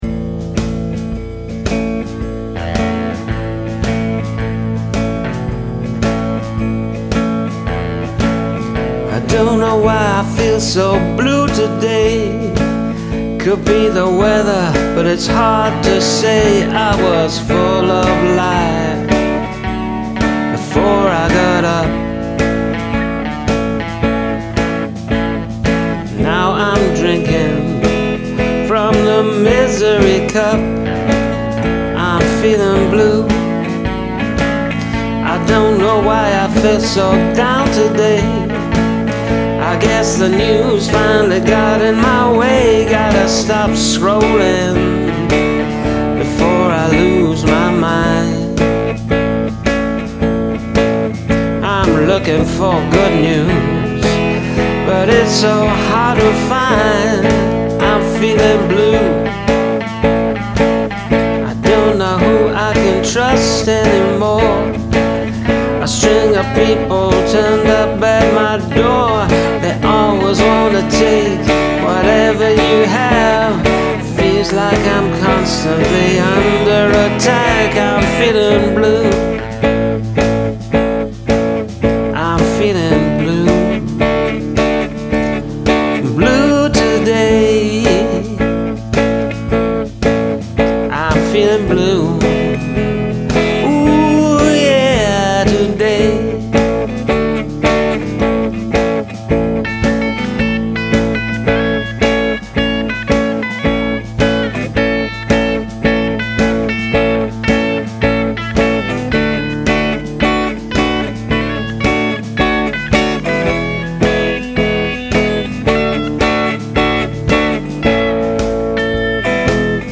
Upbeat blues.
Second guitar.